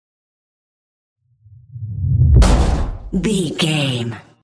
Dramatic hit deep scary trailer
Sound Effects
Atonal
heavy
intense
dark
aggressive